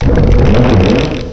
cry_not_boldore.aif